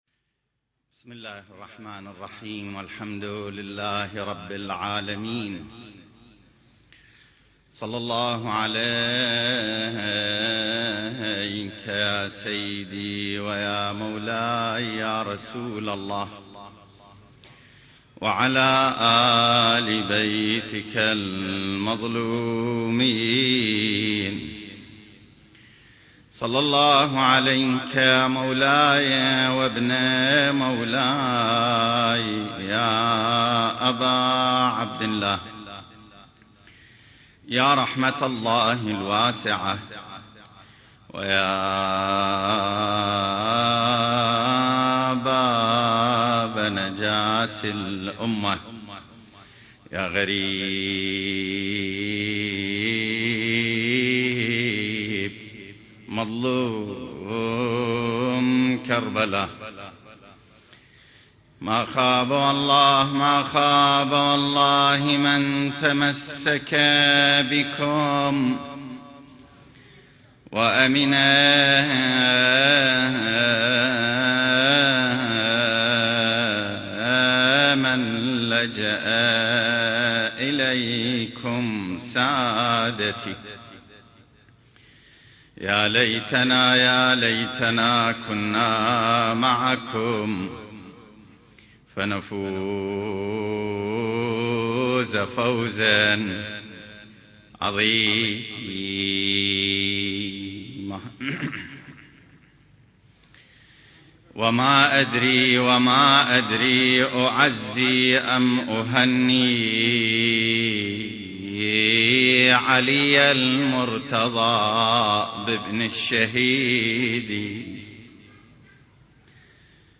سلسلة محاضرات: نفحات منبرية في السيرة المهدوية المكان